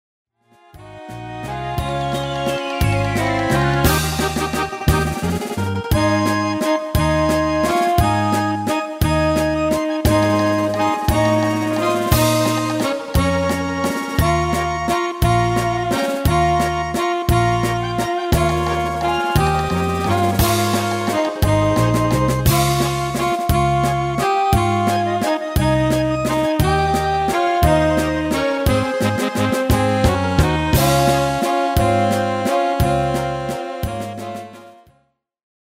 Demo/Koop midifile
Genre: Nederlandse Oldies
- Géén vocal harmony tracks
Demo's zijn eigen opnames van onze digitale arrangementen.